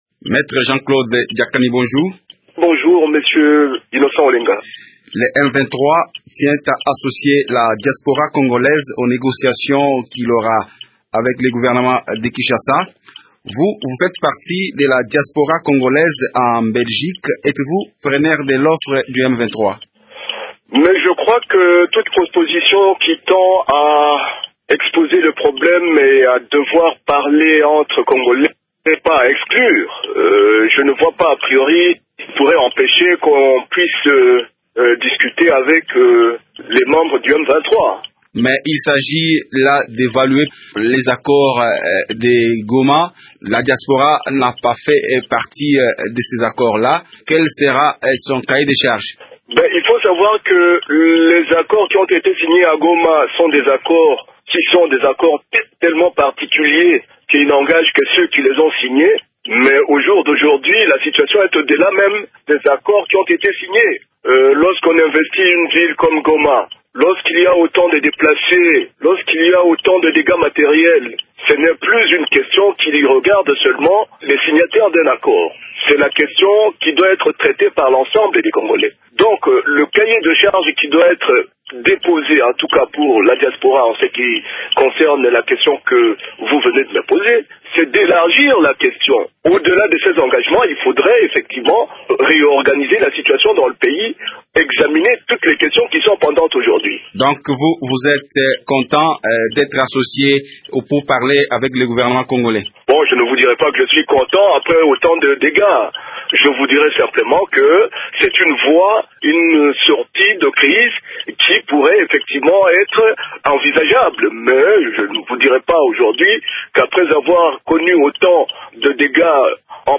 Il est membre influent dans la diaspora congolaise en Belgique.